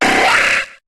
Cri d'Insécateur dans Pokémon HOME.